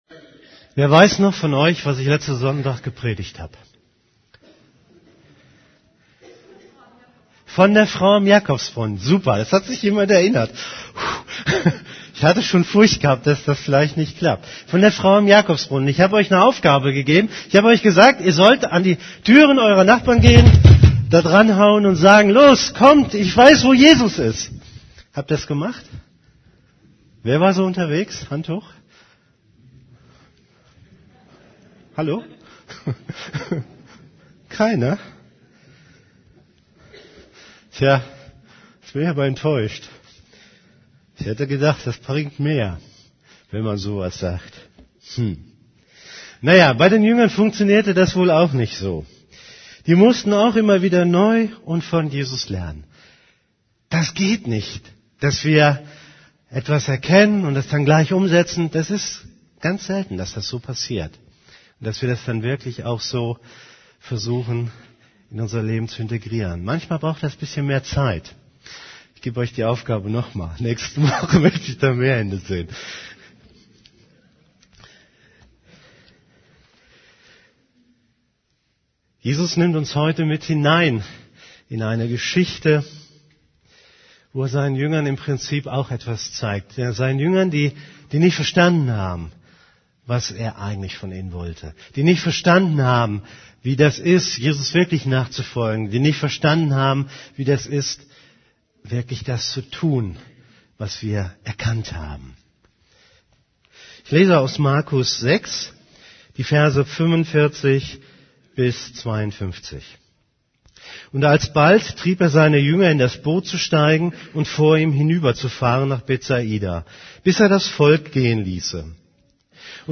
> Übersicht Predigten Mit Jesus Schritte wagen Predigt vom 20.